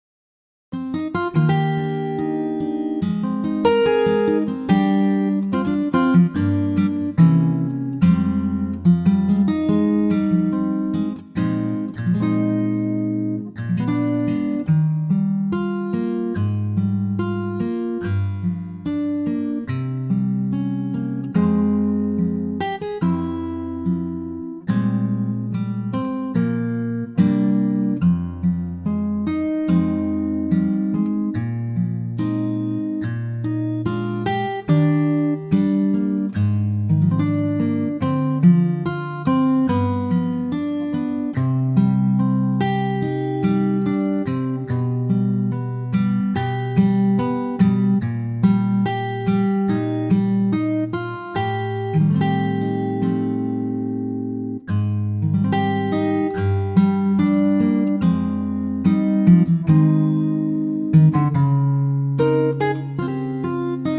key - Eb - vocal range - G to Bb
Gorgeous acoustic guitar arrangement